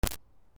ブチッ